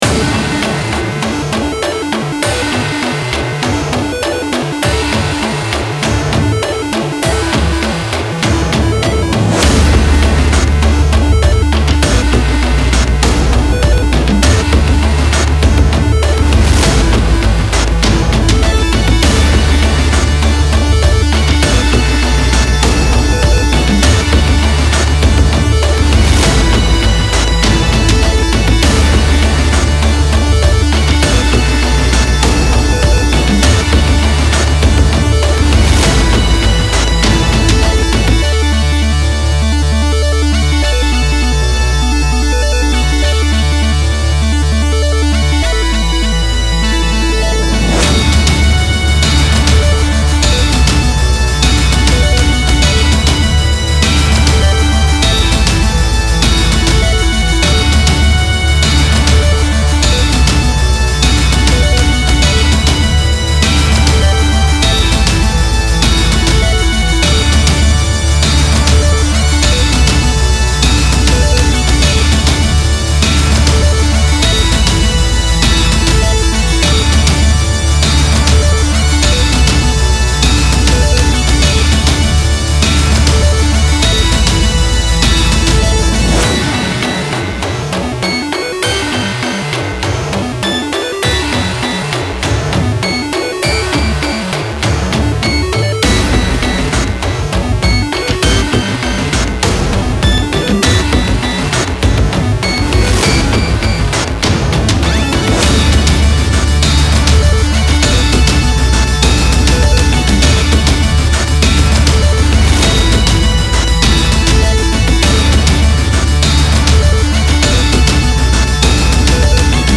Style Style Chiptune, EDM/Electronic
Mood Mood Bouncy, Uplifting
Featured Featured Drums, Synth
BPM BPM 100